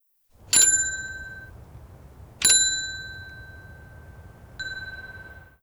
Timbre de una bicicleta (varios)
Sonidos: Transportes